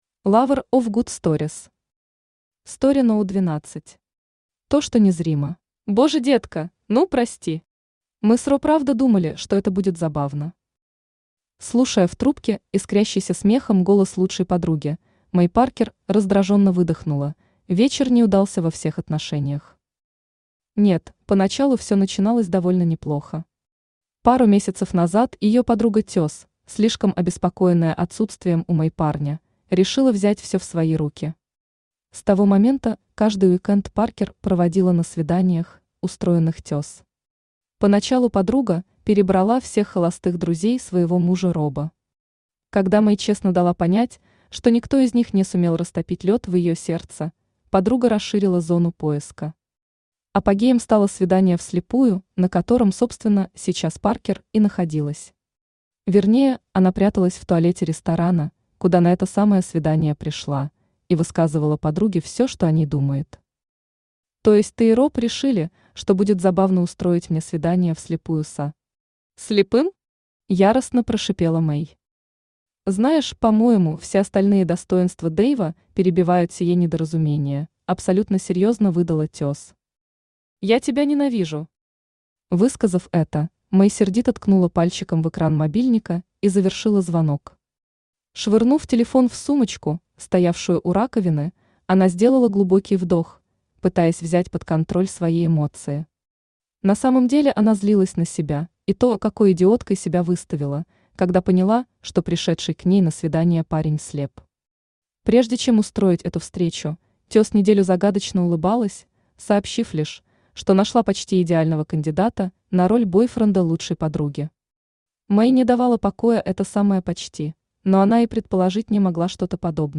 Аудиокнига Story № 12. То, что незримо | Библиотека аудиокниг
То, что незримо Автор Lover of good stories Читает аудиокнигу Авточтец ЛитРес.